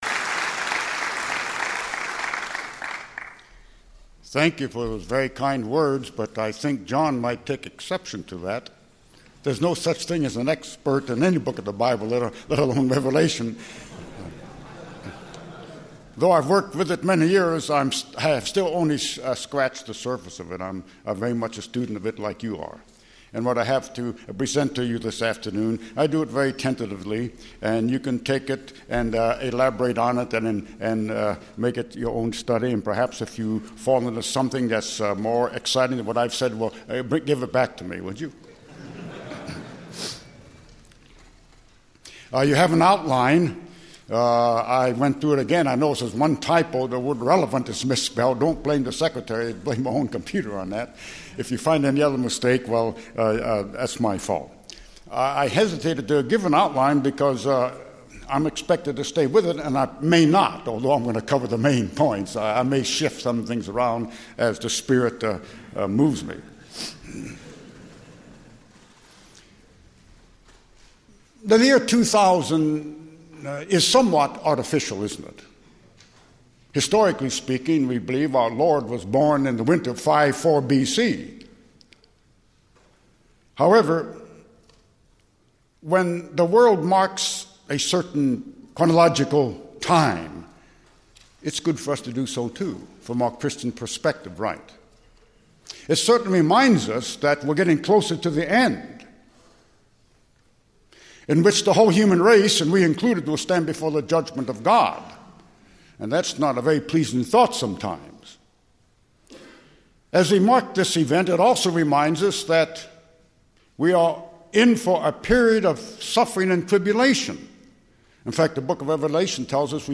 10th theological symposium
Bible Study; Lecture